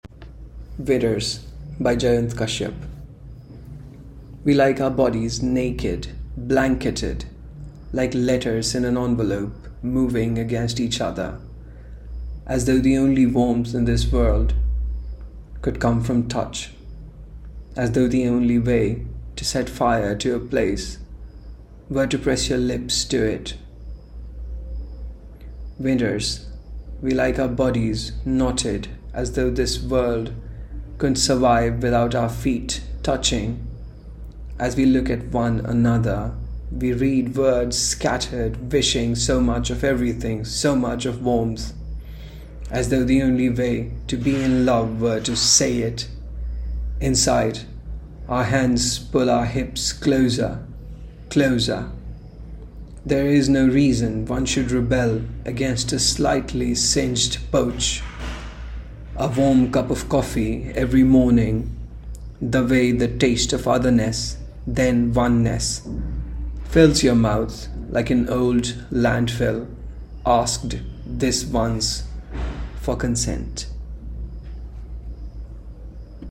Press play to hear the author read their piece.